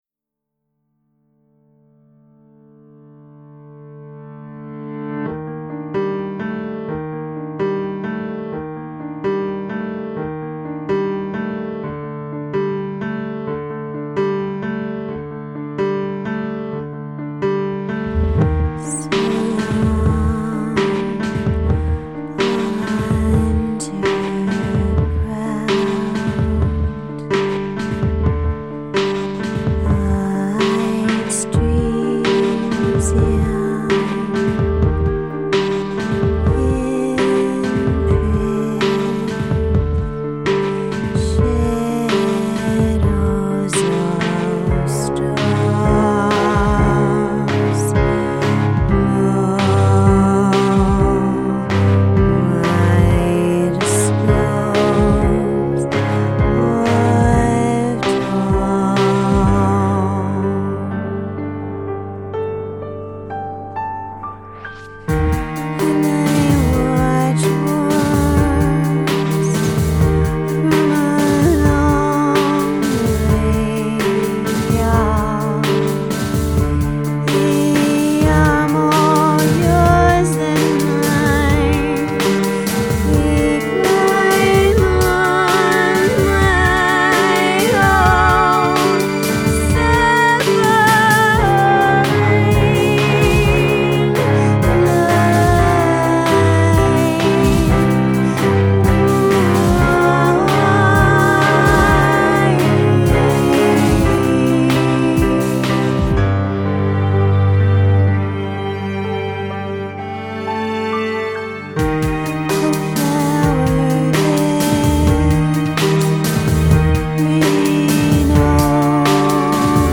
as a singer
recording studios in Menlo Park
WYW-NewDrumsStrings.mp3